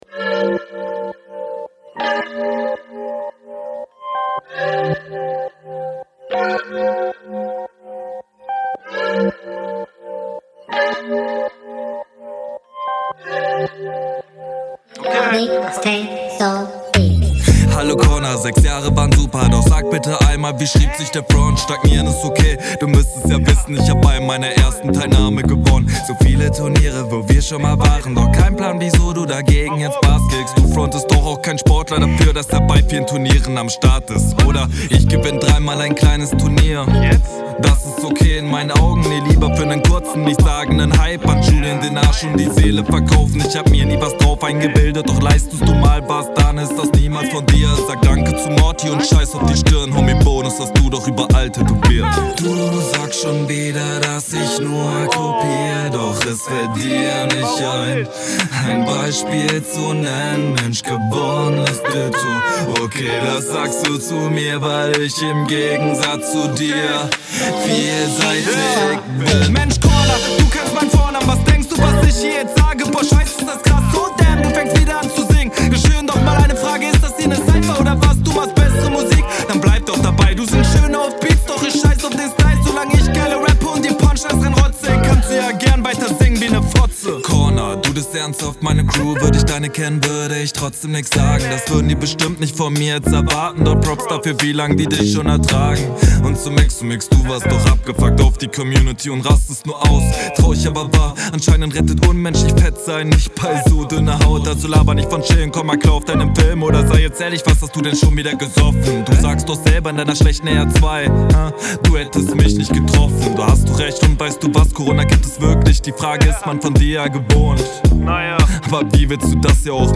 Stimme könnte hier etwas lauter.
Dieser Beat macht mich fertig.